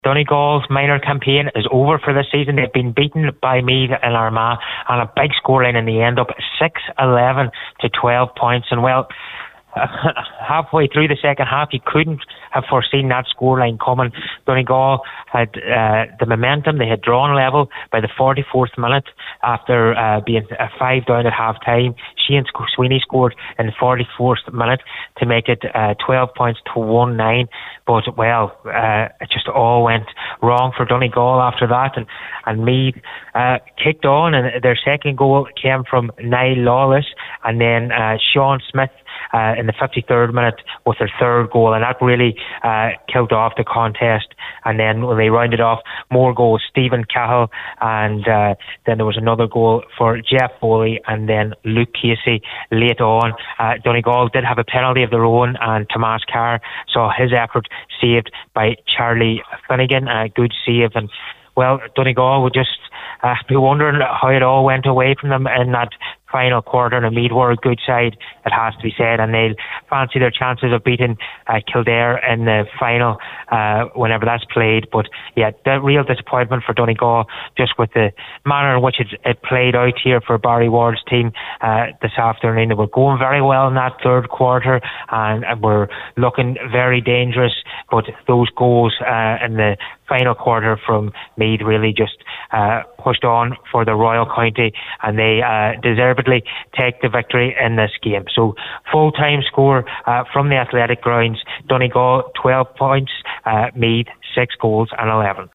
the full time report for Highland Radio Sport…